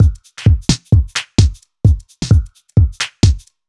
Index of /musicradar/uk-garage-samples/130bpm Lines n Loops/Beats
GA_BeatnPercE130-05.wav